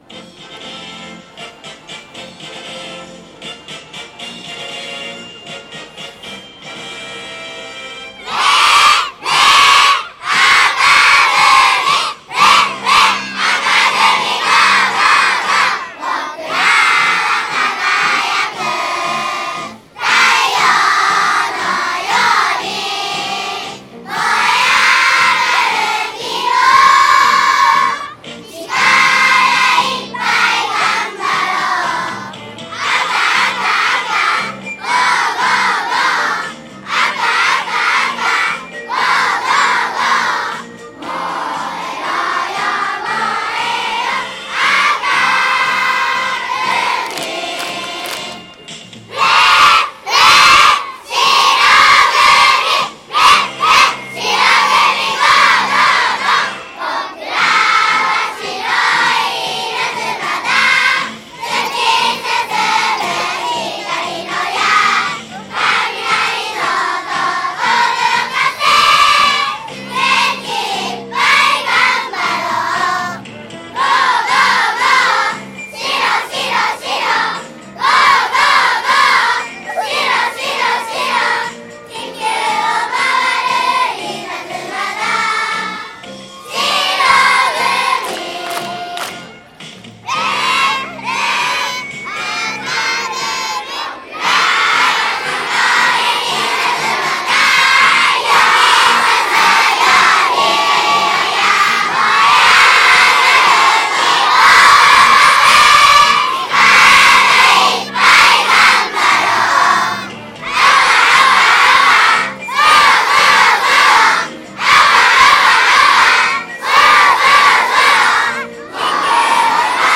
令和７年度　運動会
１　開　会　式
パワフルな歌声に、運動会にかける気持ちが込められていました。